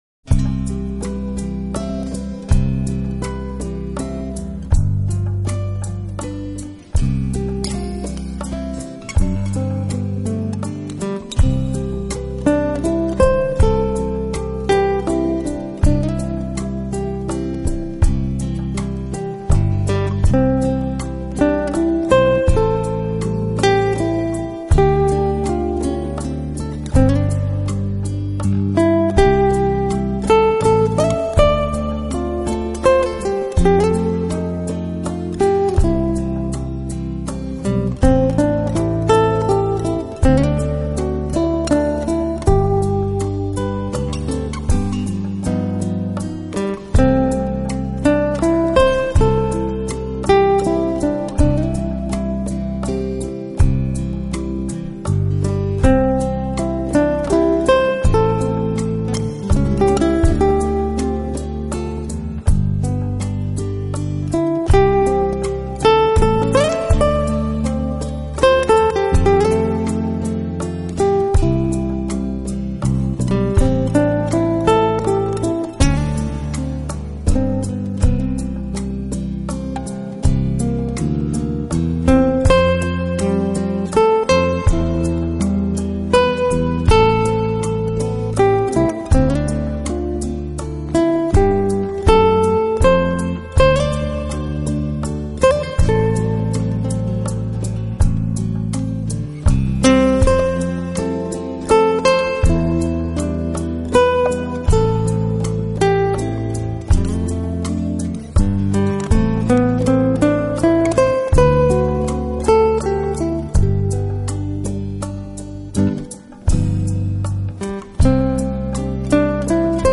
吉他演奏
☆ 拉丁风情与上海外滩情怀共同交织的隽永之作
☆ 耳熟能详的优美旋律、举重若轻的演奏技巧、质感绝佳的顶级录音